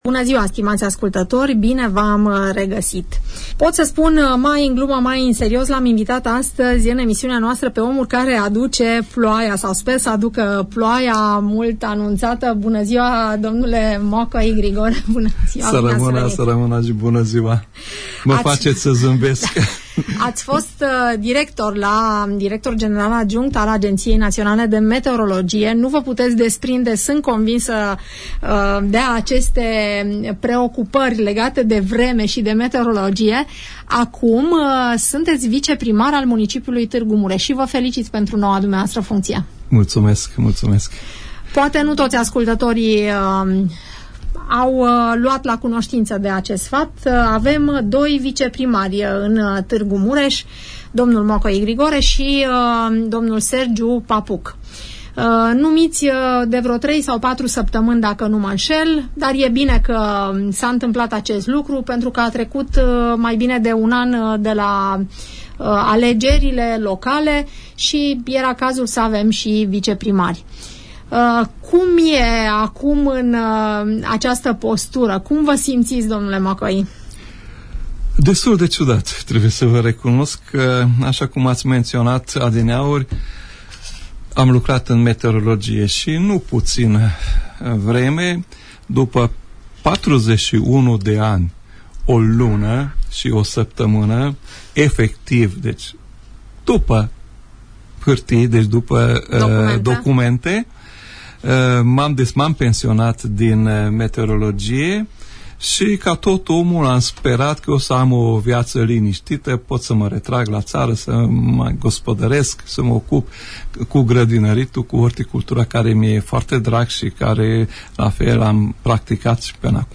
Dl Makkai Grigore, fost director general adjunct al Agenției Naționale de Meteorologie din România, este unul din cei doi viceprimari ai municipiului Tg Mureș.